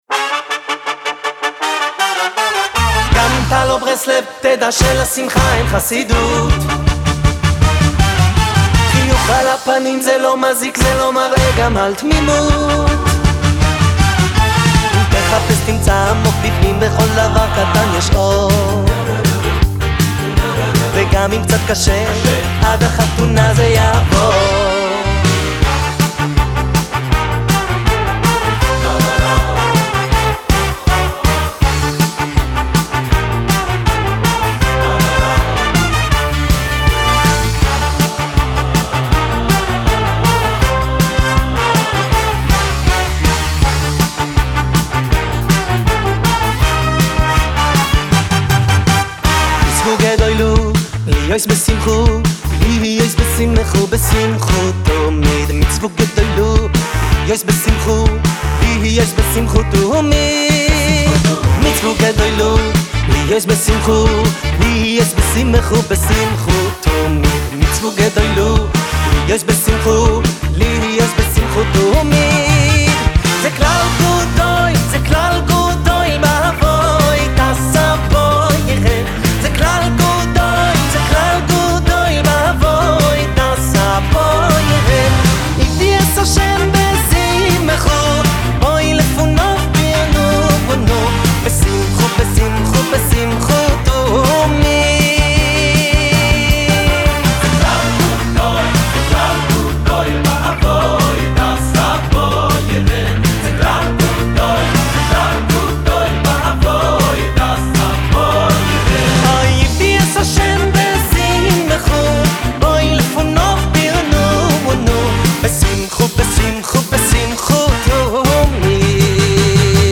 שיר קצבי חדש. היוצר והמבצע הוא דמות חדשה בשמי הזמר החסידי
האנרגטי והשמחה המתפרצת.